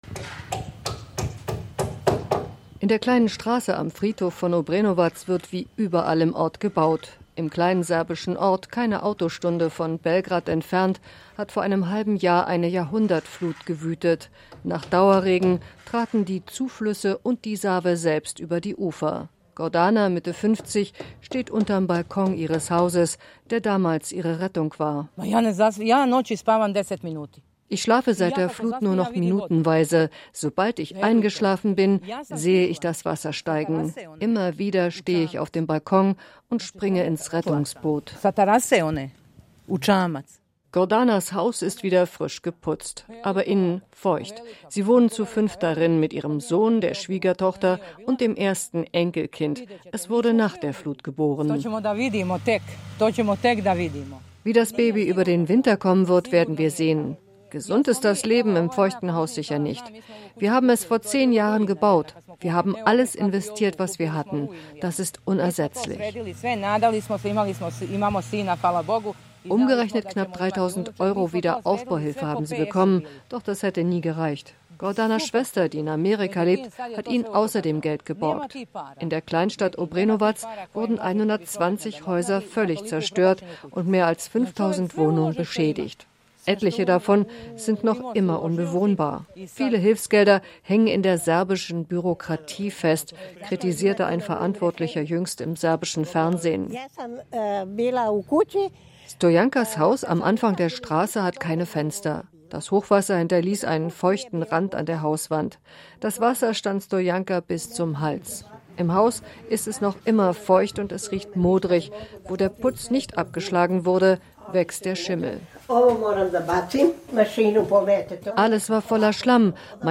Nach-der-Jahrhundertflut-im-serbischen-Obrenovac.Reportage.mp3